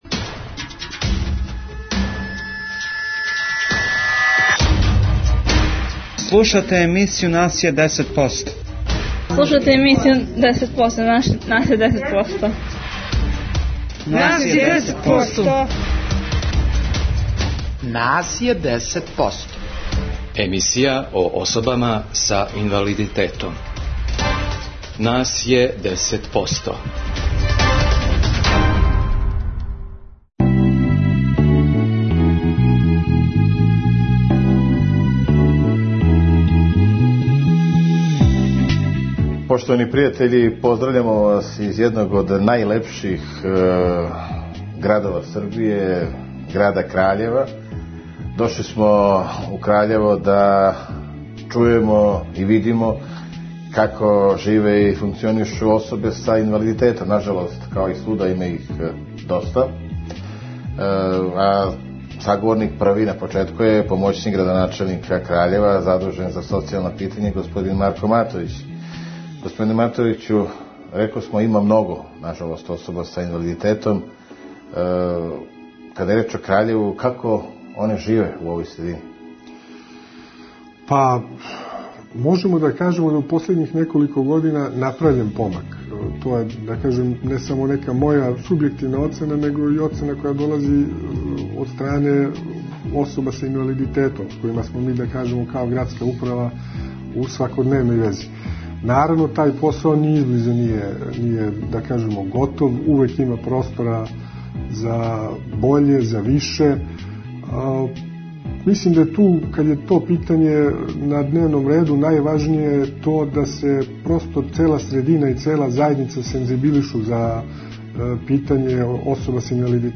Овог понедељка смо у Краљеву. Говоримо о статусу особа са инвалидитетом.